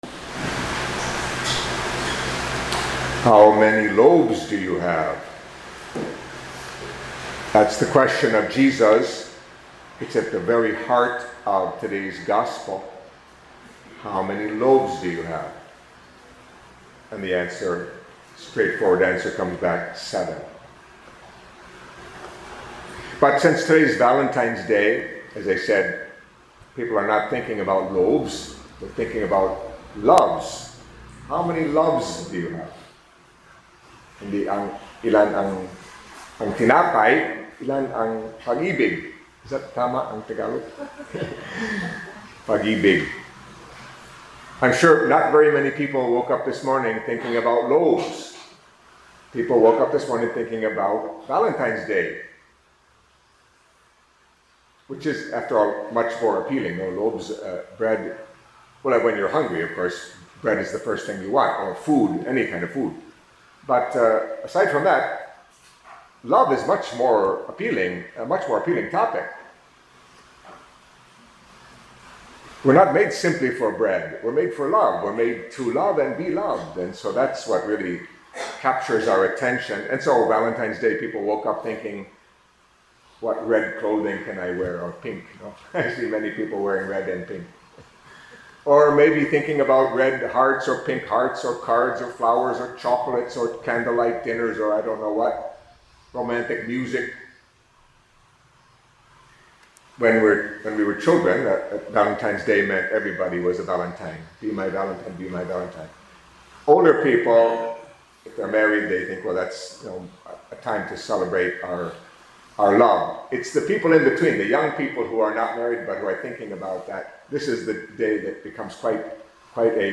Catholic Mass homily for Saturday of the Fifth Week in Ordinary Time